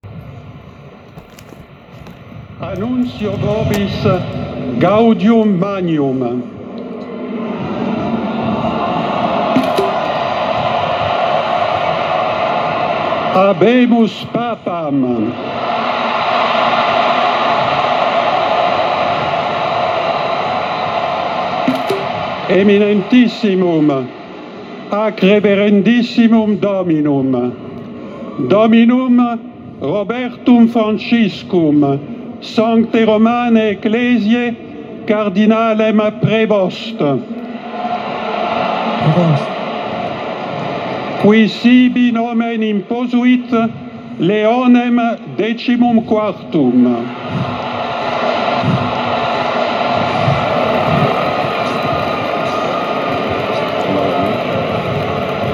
L’audio dell’annuncio del nuovo Papa ad opera del cardinale Dominique Mamberti